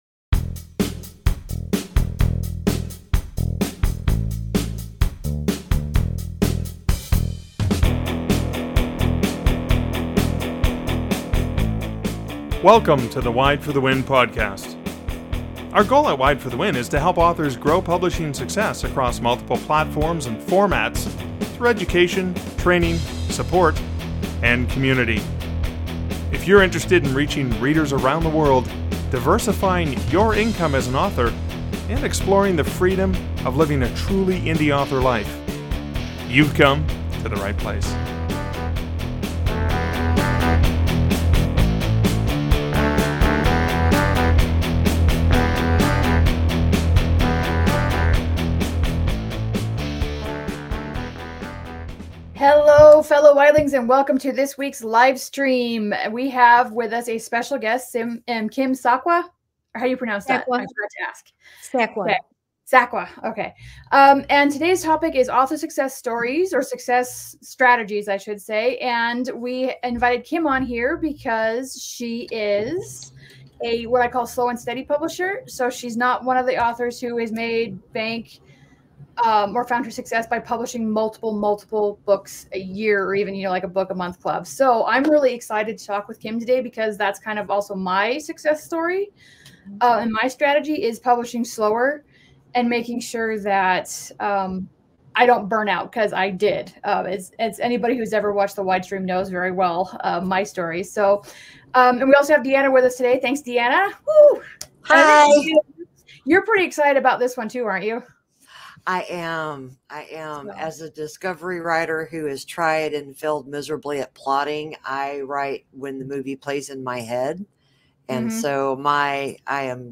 In this episode of the WIDEstream, we sit down with author